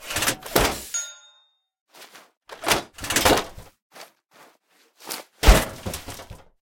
fatmanReload.ogg